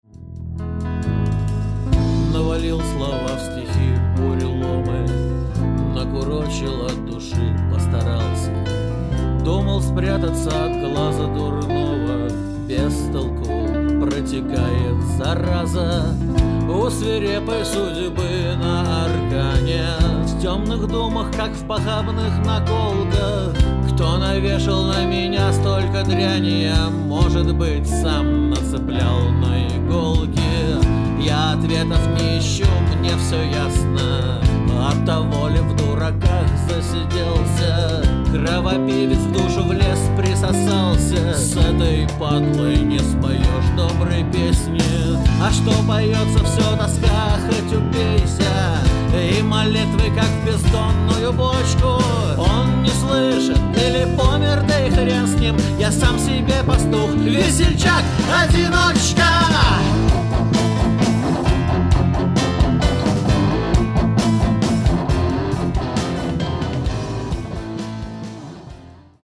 Лирические звуки клавиш
Это конечно "русский рок".